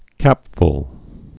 (kăpfl)